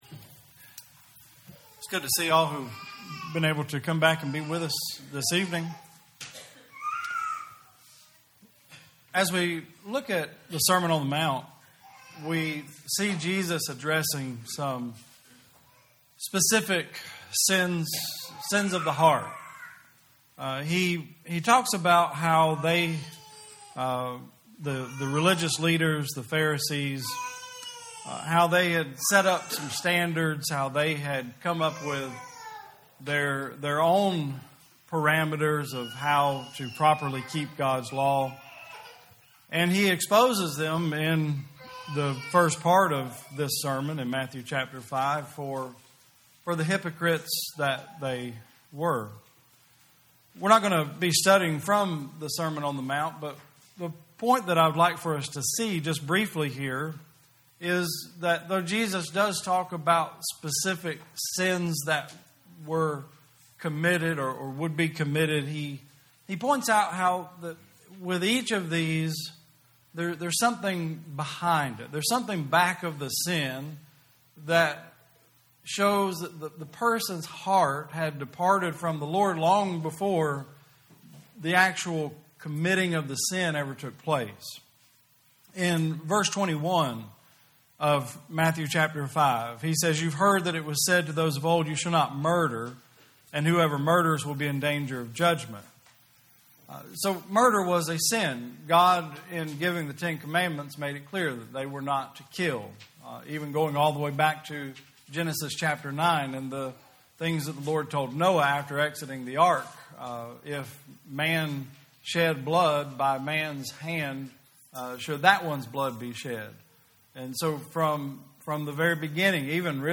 2020 Service Type: Sunday Service Preacher